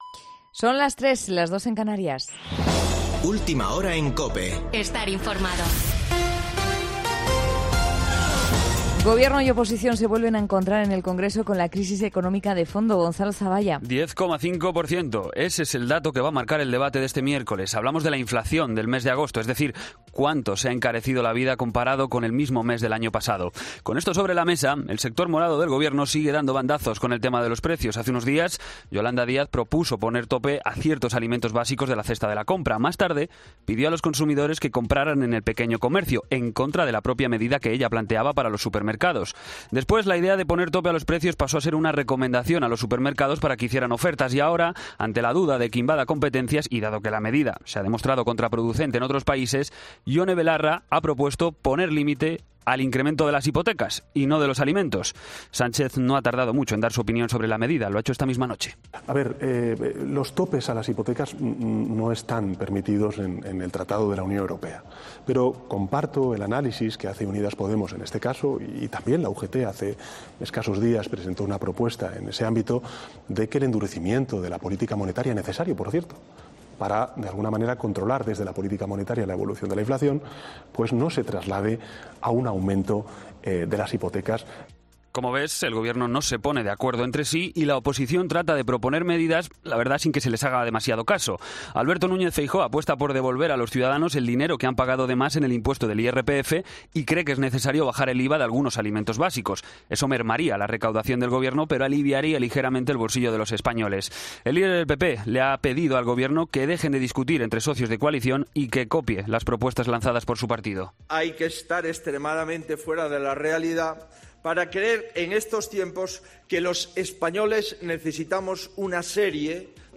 Boletín de noticias COPE del 14 de septiembre a las 03:00 horas